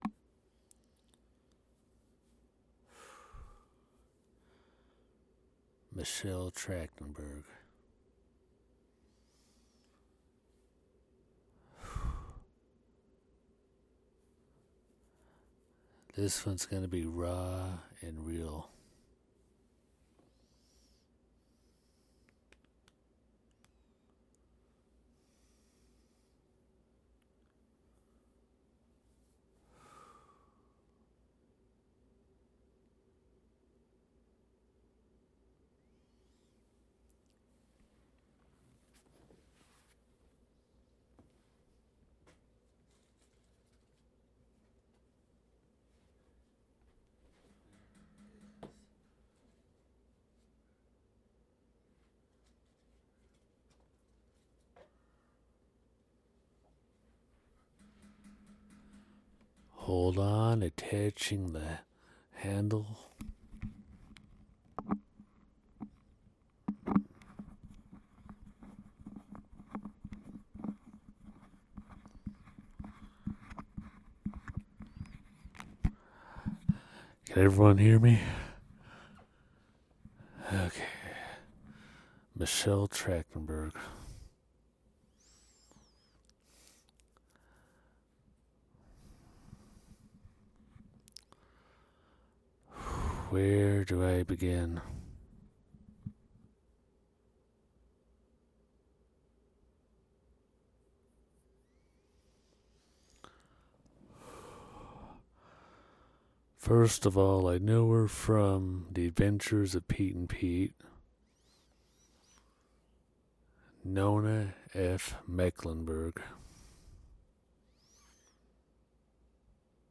On February 26, 2025, the world lost Michelle Christine Trachtenberg at the far-too-young age of 39. The following is a transcription of my live UnNews Audio eulogy.